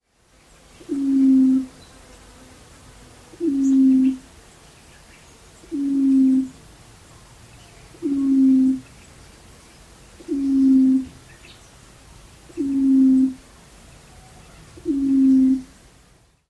The call of the Common Bronzewing is a deep, resonant and repetitive ‘oom’, which can be heard by clicking on the audio icon below.
common-bronzewing.mp3